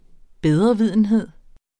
bedrevidenhed substantiv, fælleskøn Bøjning -en Udtale [ ˈbεðʁʌˌviðˀənˌheðˀ ] Betydninger bedrevidende opførsel Synonymer bedreviden besserwissen Luk ørerne for andres bedrevidenhed.